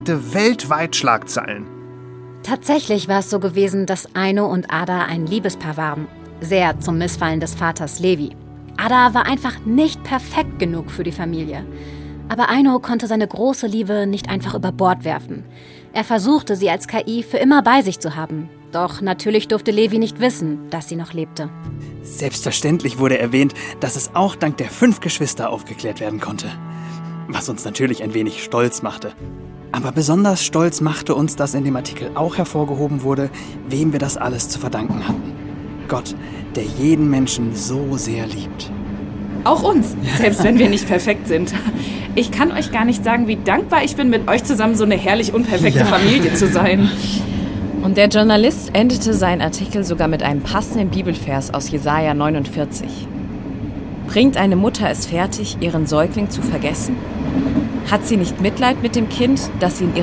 Hörspiele